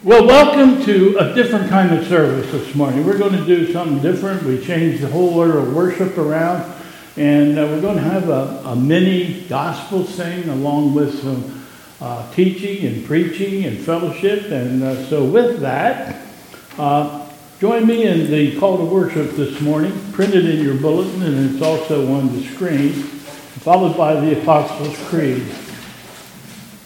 ...and Announcements